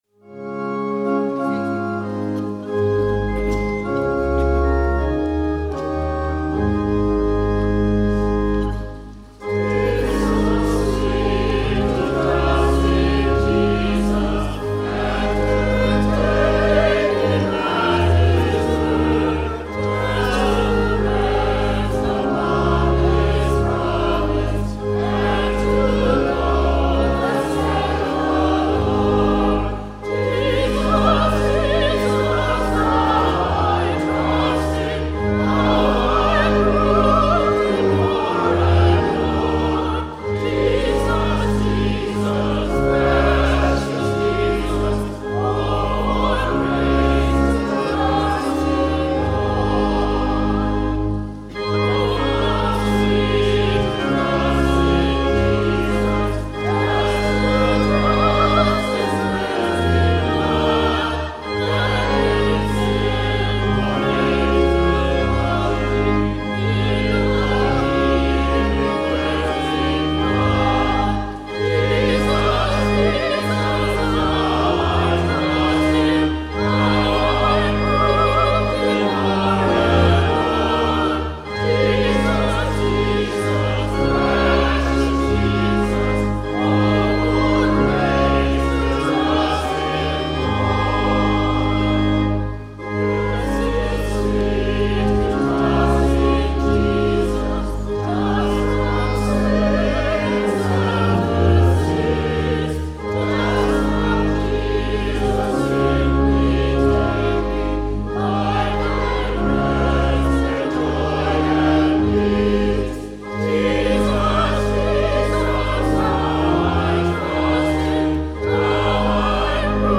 Children’s Sabbath 2018 – 9:30